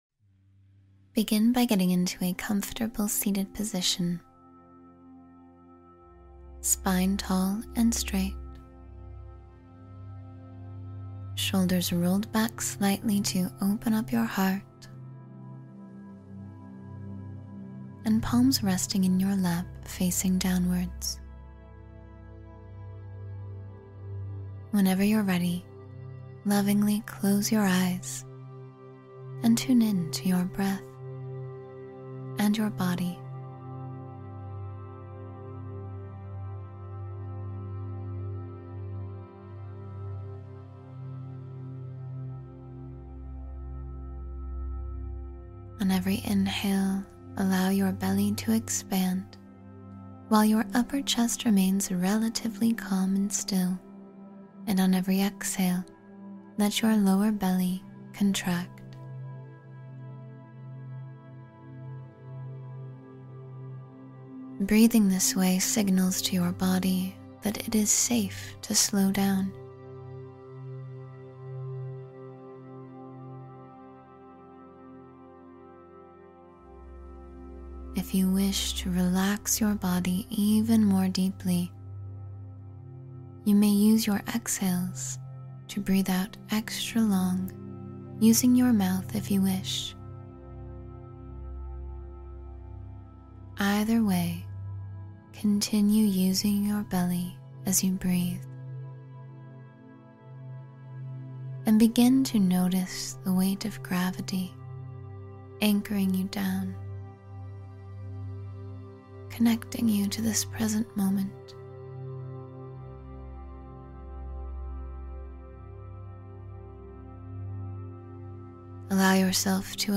Calm the Storm of Overthinking — Guided Meditation for Mental Clarity